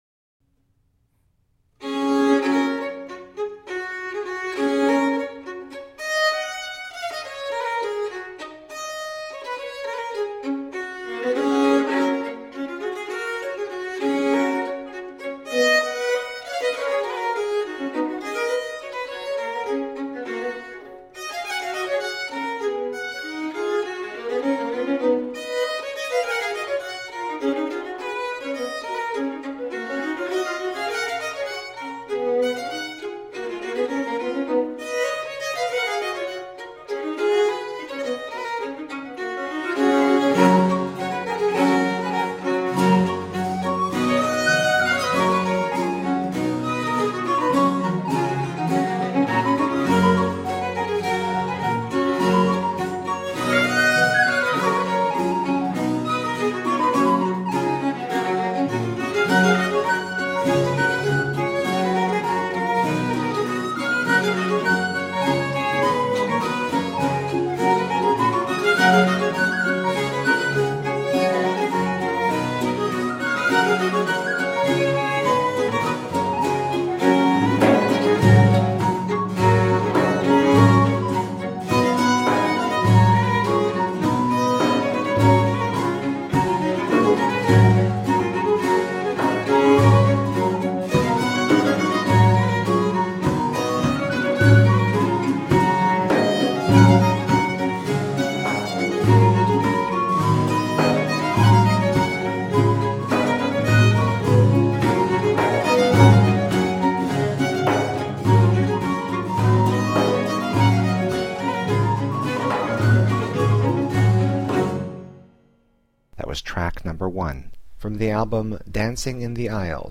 recorders and whistle
baroque violins
baroque 'cello, viola da gamba
harpsichord
theorbo, baroque guitar
percussion